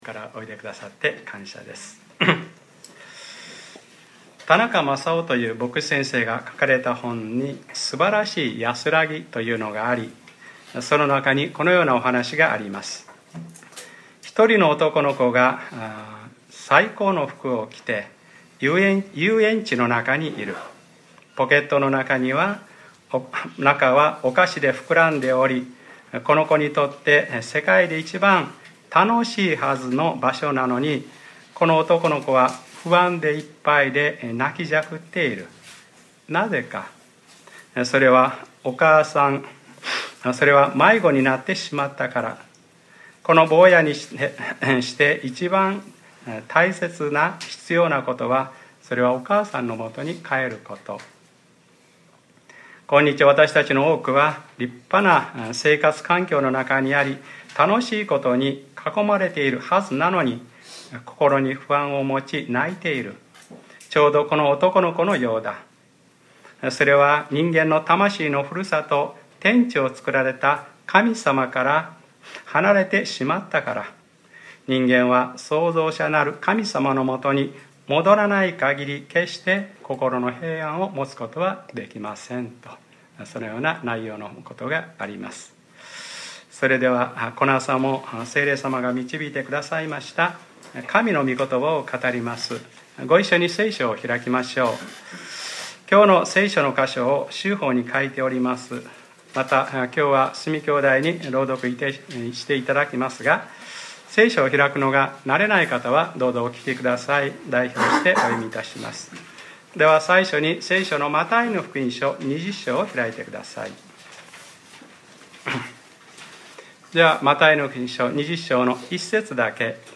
2014年7月20日（日）礼拝説教 『あなたと同じだけあげたいのです』